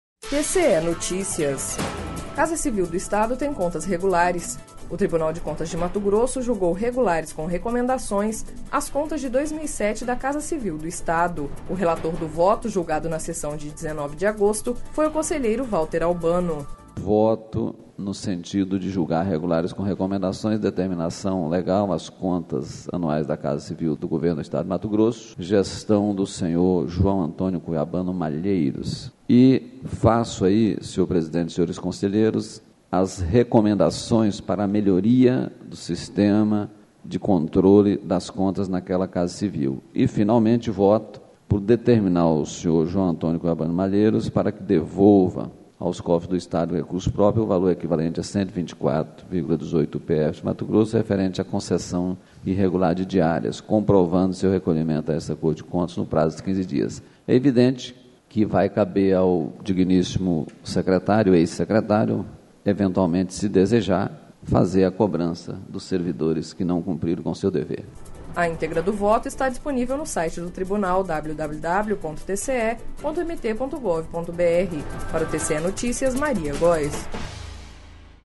Sonora: Valter Albano - conselheiro TCE-MT